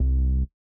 ITA Bass C1.wav